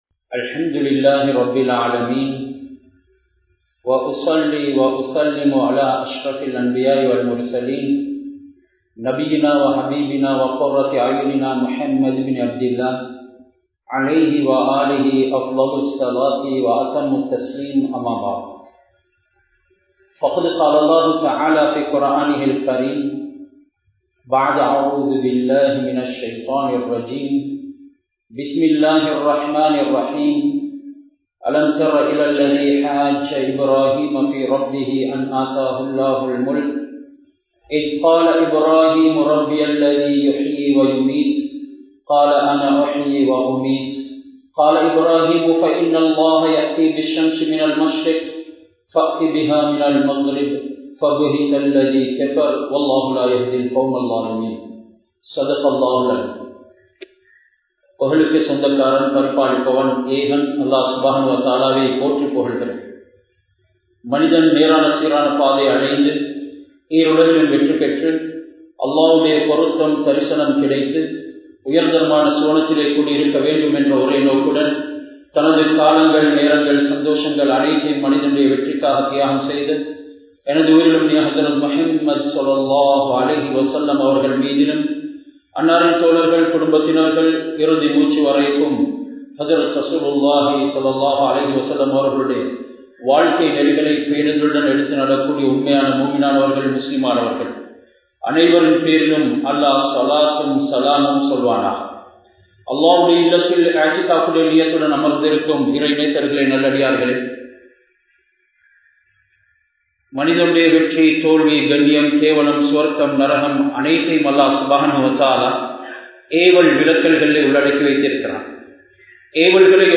Eidul Alha & Ottrumai (ஈதுல் அல்ஹா & ஒற்றுமை) | Audio Bayans | All Ceylon Muslim Youth Community | Addalaichenai